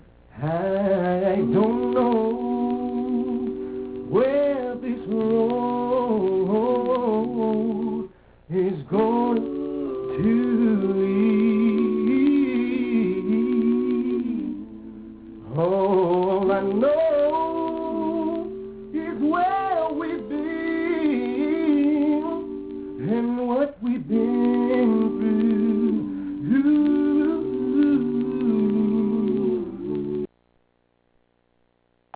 Live Clips